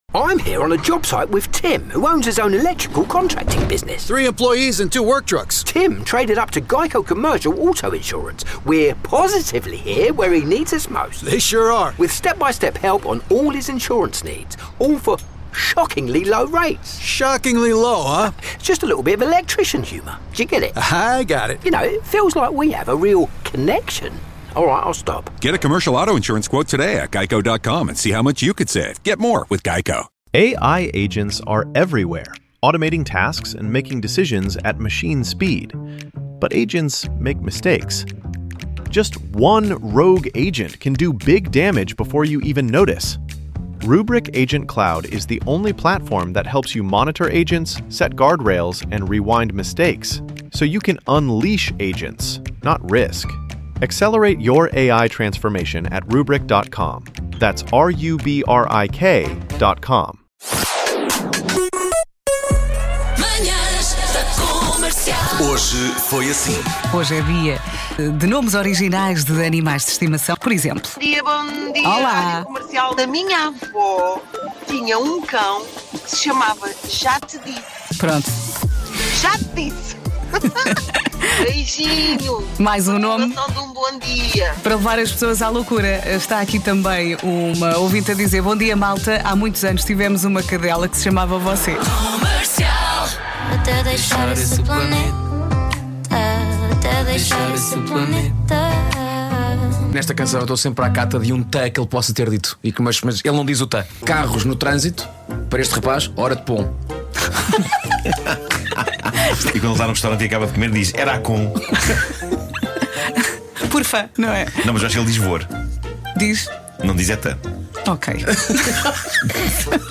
… continue reading 1993 एपिसोडस # Comédia limpa # Entretenimento # Portugal # Nuno Markl # Pedro Ribeiro # Ricardo Ara�jo Pereira # Comédia # Portugal Comédia